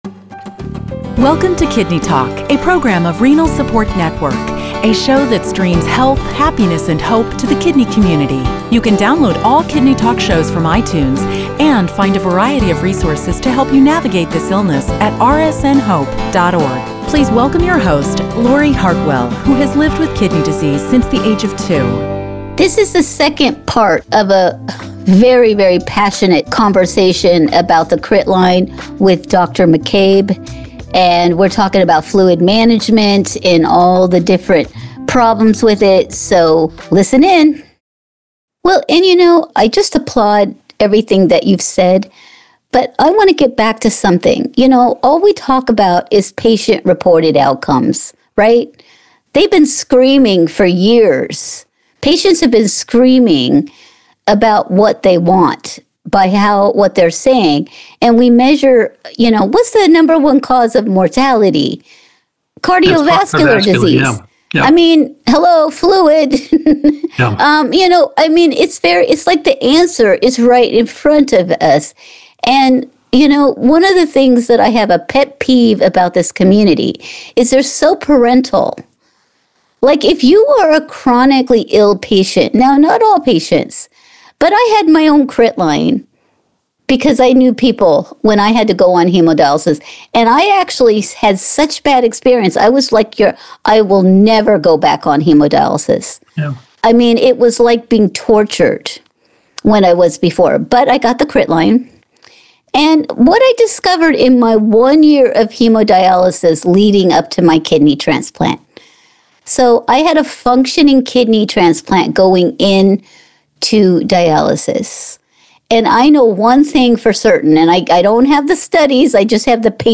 In this two-part conversation